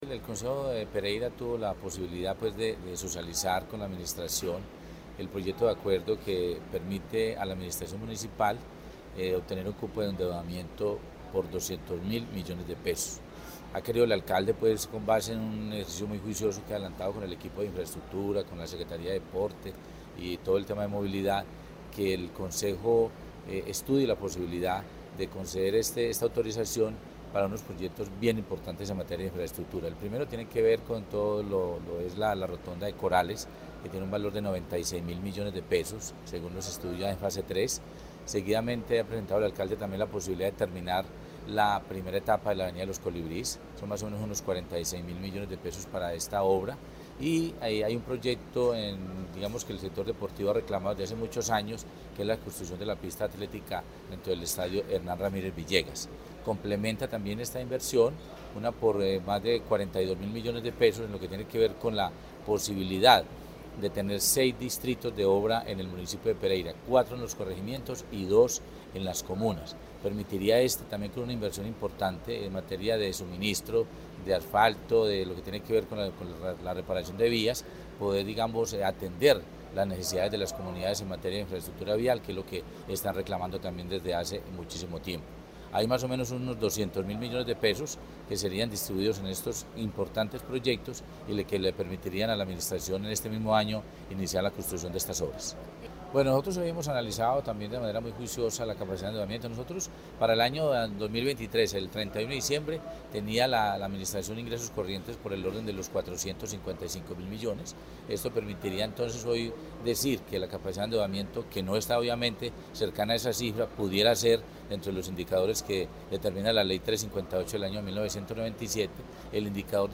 El secretario de Hacienda, Jorge Alexis Mejía, explicó el estado actual de las finanzas y la capacidad que tiene el municipio para asumir estos compromisos.
JORGE_ALEXIS_MEJIA_BERMUDEZ_SECRETARIO_DE_HACIENDA_DE_PEREIRA.mp3